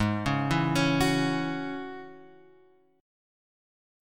G# 7th Suspended 4th Sharp 5th